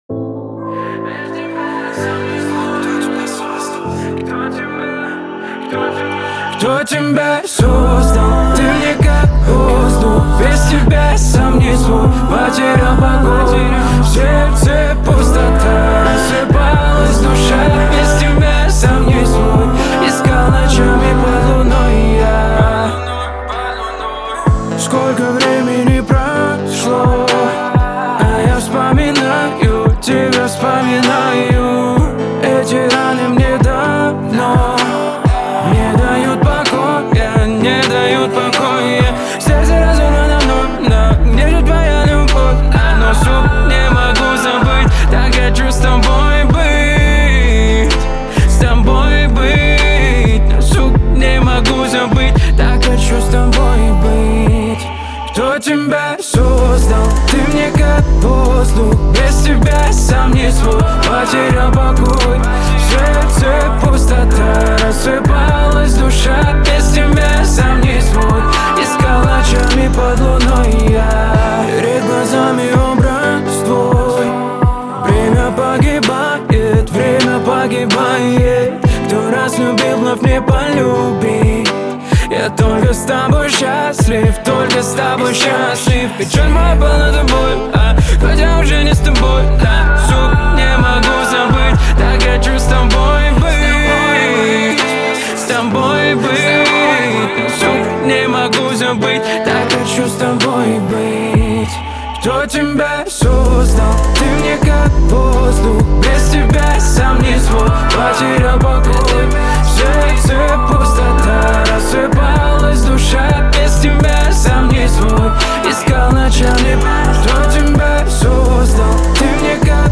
это глубокая и эмоциональная композиция в жанре поп-рок.